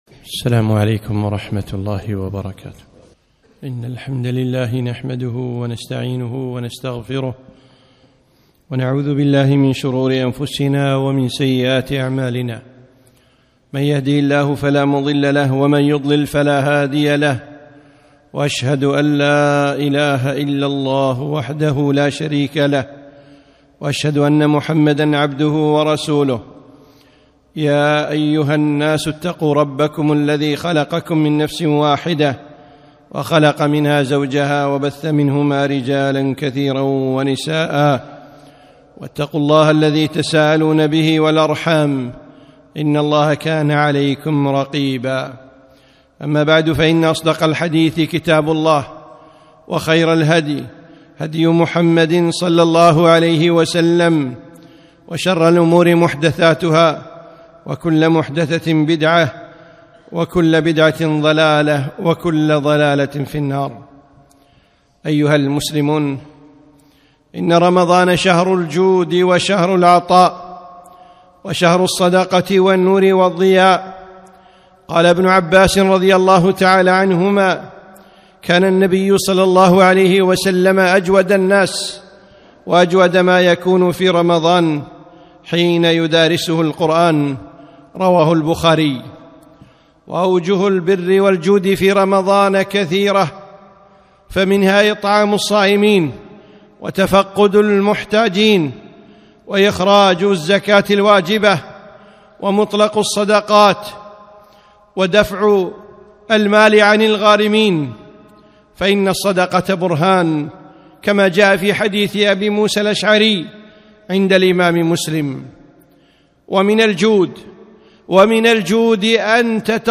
خطبة - فضل الوقف